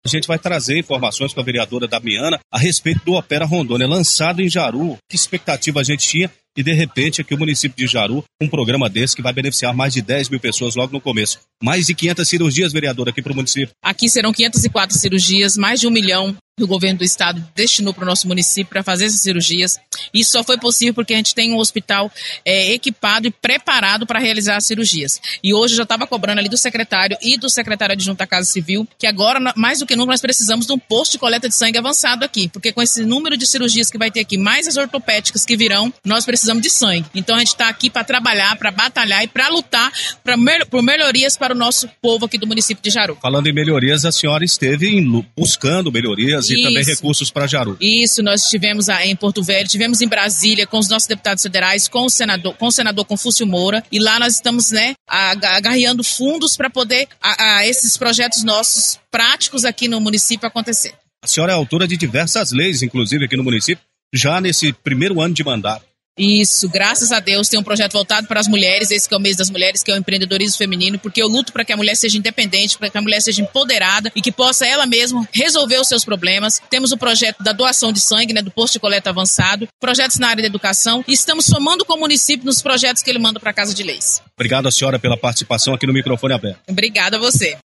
Ainda durante a entrevista para a Massa FM Jaru 91.1 Mhz, a parlamentar lembrou que é defensora ferrenha do empoderamento das mulheres de forma geral e que tem projetos de leis nesse sentido.
Entrevista-Damiana-Posto-de-Coleta-de-Sangue.mp3